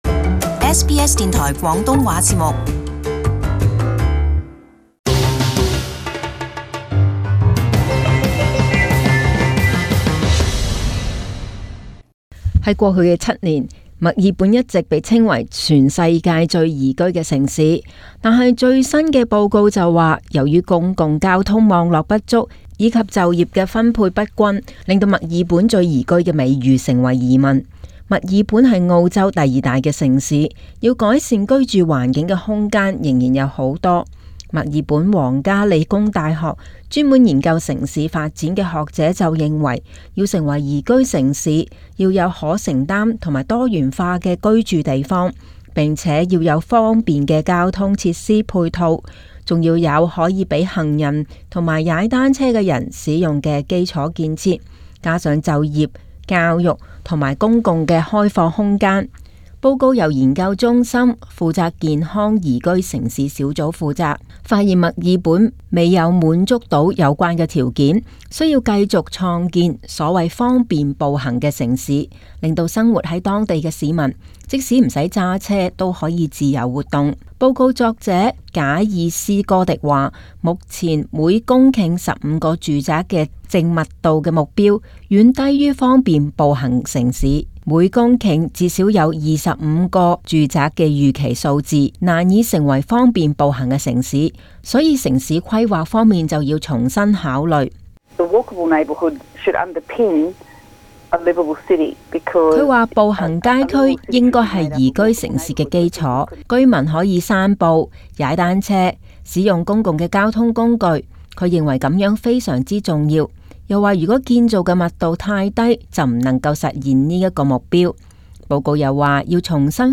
【時事報導】墨爾本宜居度